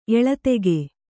♪ eḷatege